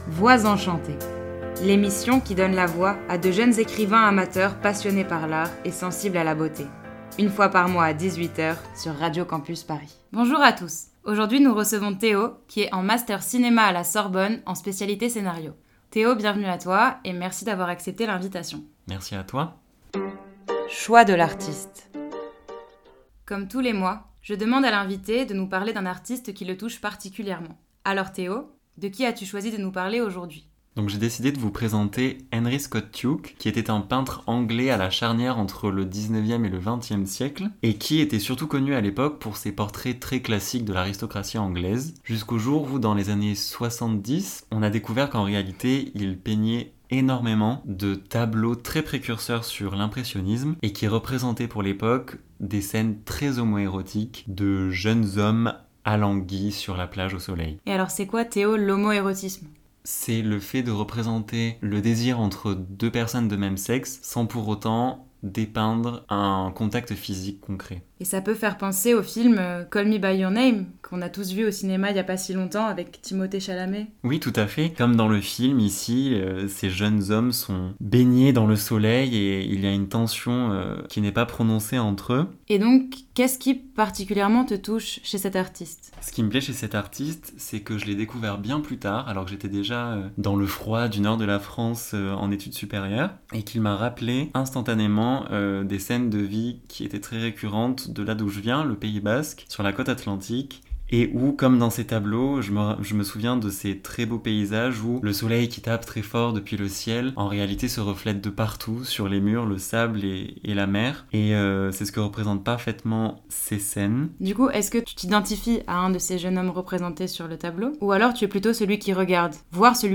Type Entretien Culture